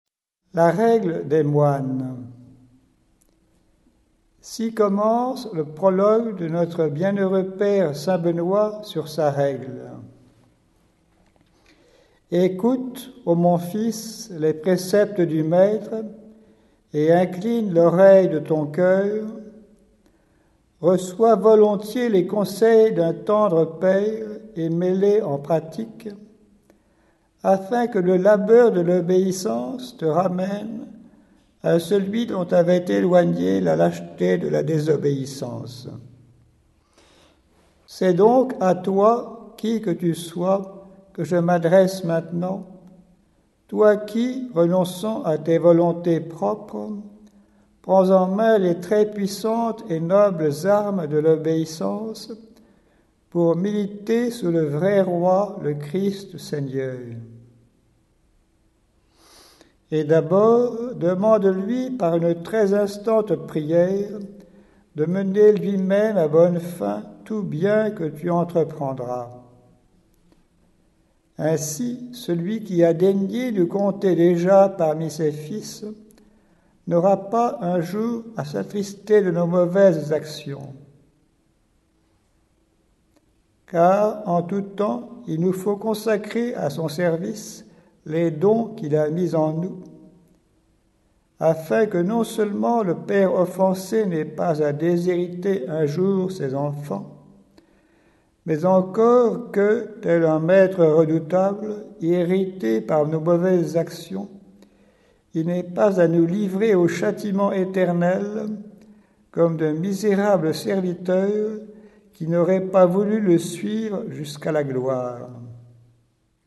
Diffusion distribution ebook et livre audio - Catalogue livres numériques
Sa lecture par une dizaine de moines de l'abbaye bénédictine de Saint-Martin de Ligugé lui restitue toute son ampleur